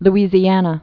(l-ēzē-ănə, lzē-)